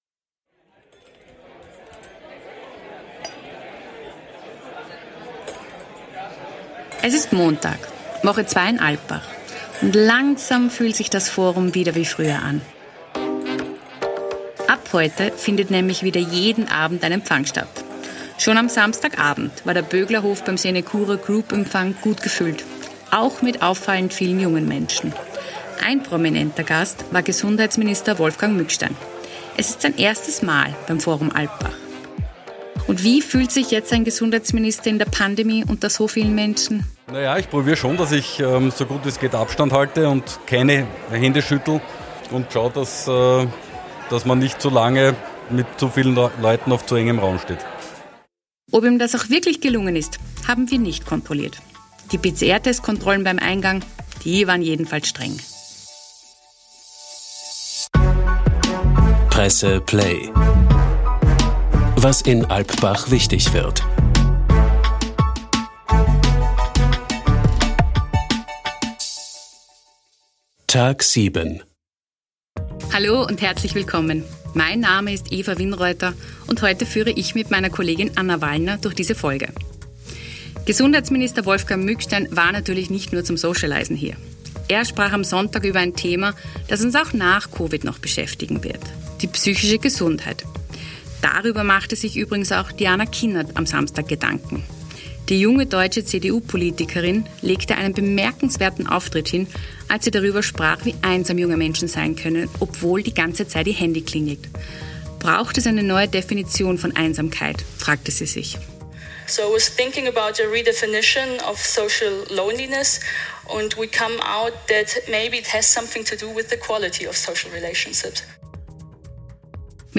Nobelpreisträger Joseph E. Stiglitz spricht in Alpbach über das Kriegs-Trauma der USA in Afghanistan, die Zeit nach Trump und darüber, was ihm am meisten Sorgen macht. Außerdem hat in Alpbach am Wochenende erstmals seit Covid der Empfangsreigen wieder begonnen.